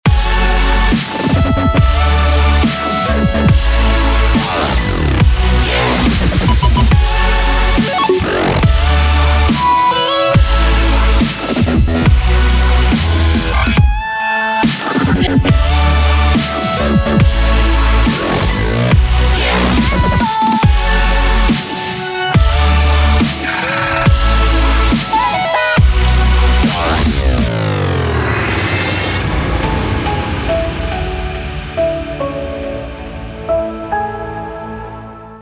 • Качество: 192, Stereo
Очень красивая Дабстеп мелодия!